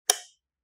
switch1.mp3